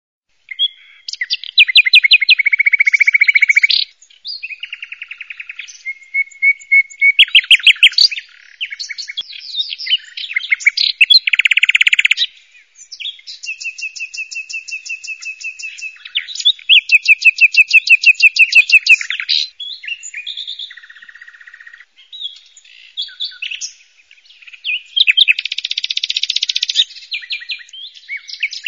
Tierstimmen